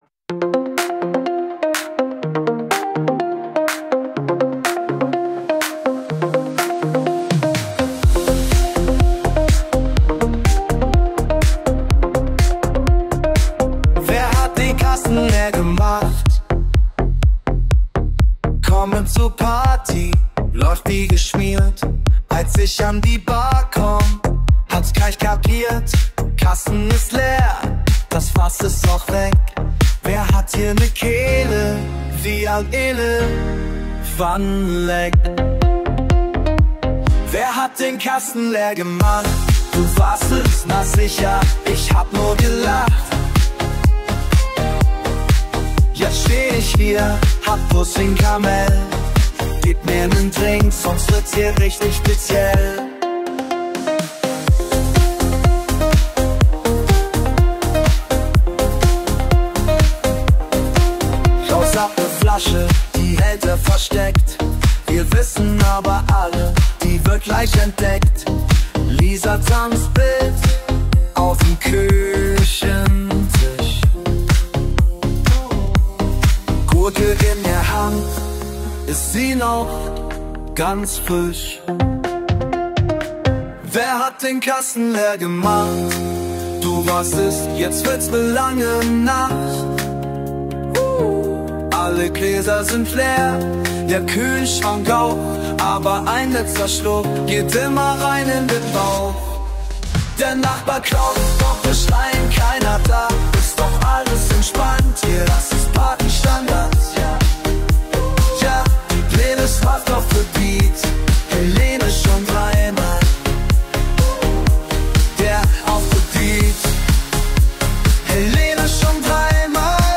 Dort habe ich den Text eingefügt, und sogar diese Überschrift, die er als erste Zeile singt.
Auch nicht schlecht, aber im ersten ist etwas mehr Wumms, was mir persönlich bei einem Partysong besser gefallen hat.